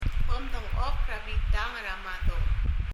発音